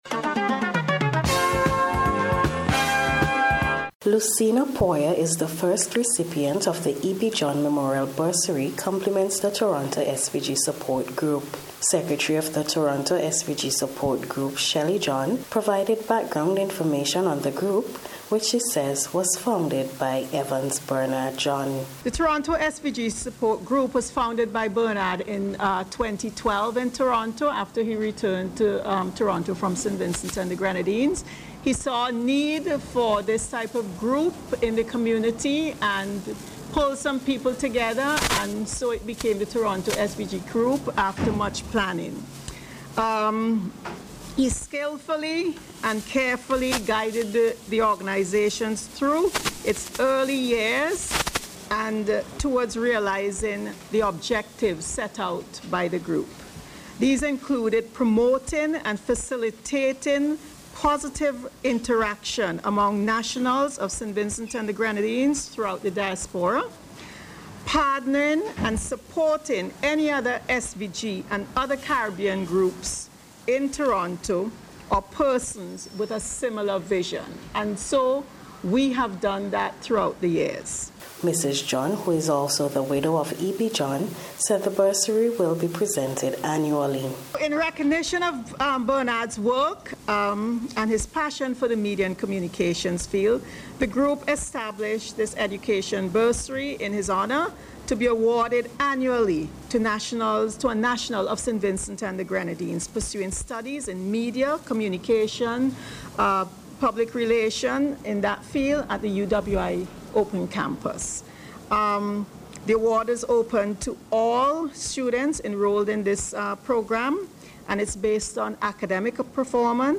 NBC’s Special Report for April 19th 2022 – NBC Radio SVG